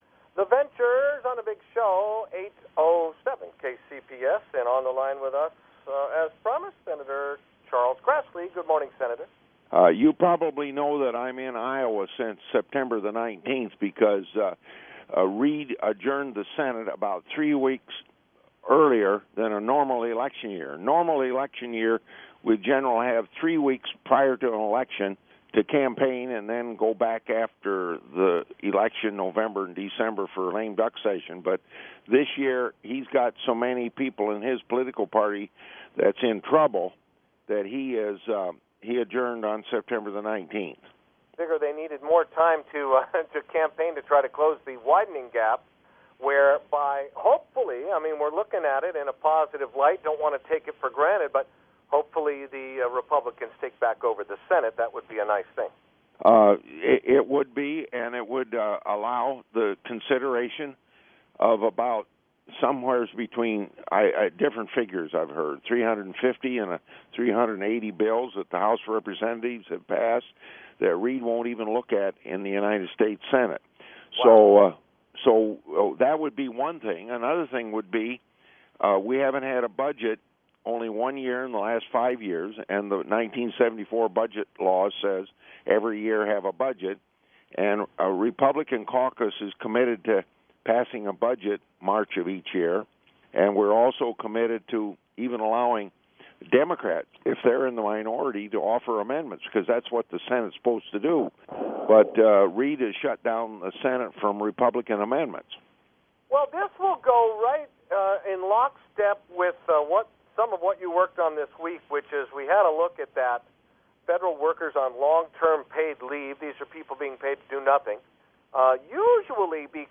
Grassley Live on KCPA